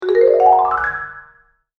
マリンバグリッサンド2.mp3